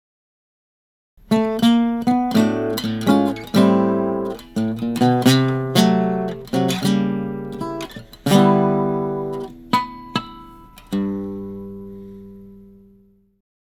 combine chords and single string fills over the four bar chord progression: Am/// D7/// G/// G///